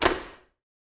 garbage clap.wav